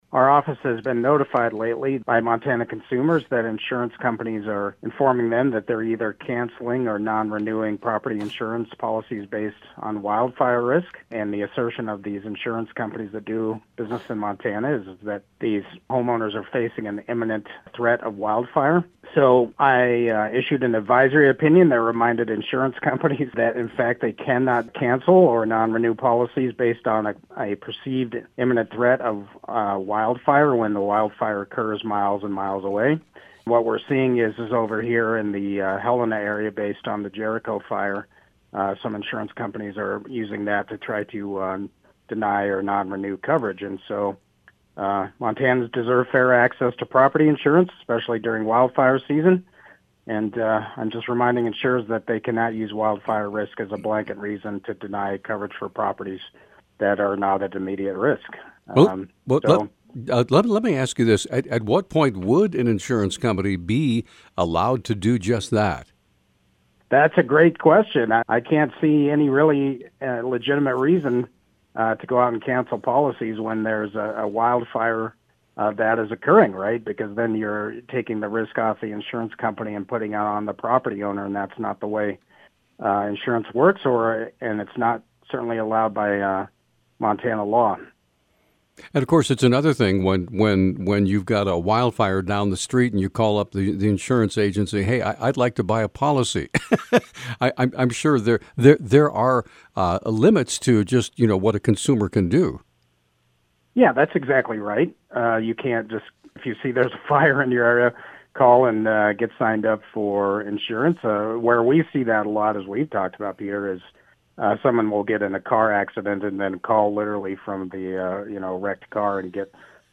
Missoula, MT (KGVO-AM News) – With Montana firmly in the grip of a hot, dry summer, I spoke with Montana State Auditor and Commissioner of Insurance James Brown on Friday about the rules around fire, or what is termed hazard insurance for your home and property.